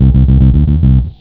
FINGERBSS2-L.wav